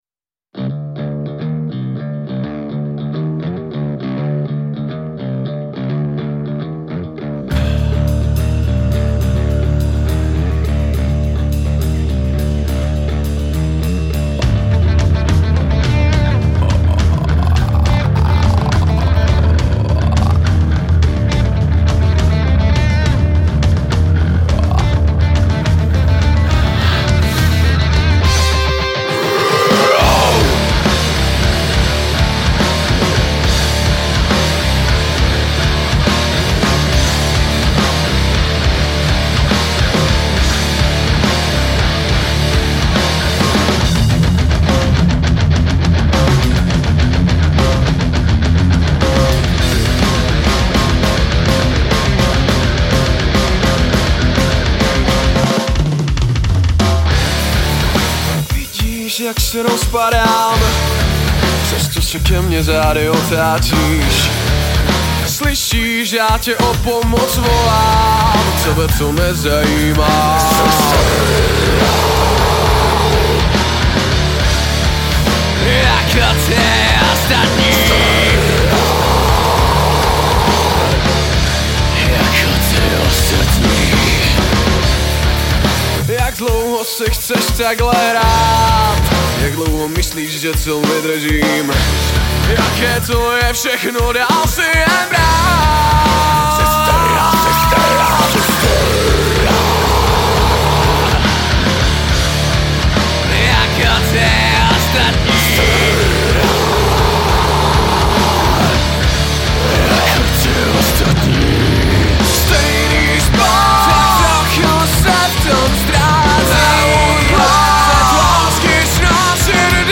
Žánr: Metal/HC
Debutové album ostravské metalové kapely
hutnými thrashovými kytarami a výraznou rytmičností skladeb.